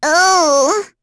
Lavril-Vox_Damage_02.wav